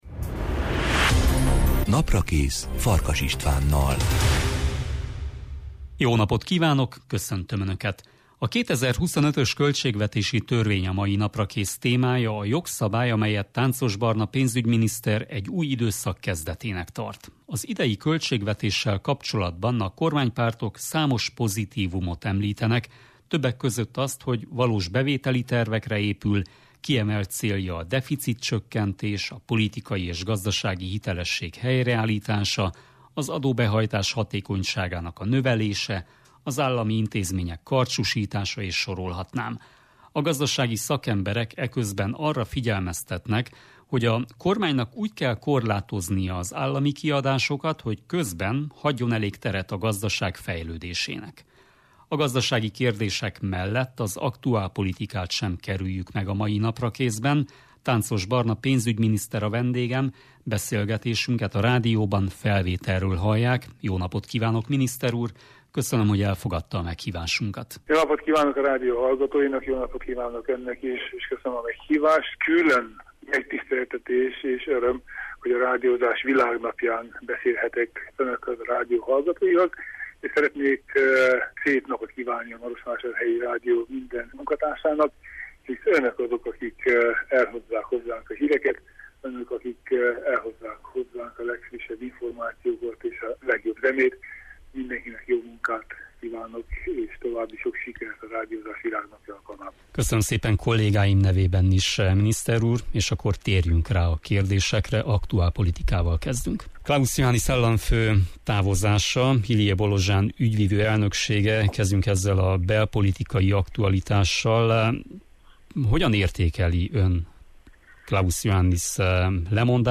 Tánczos Barna pénzügyminiszter a vendégem.